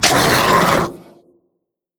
npc_draugr_injured_04.wav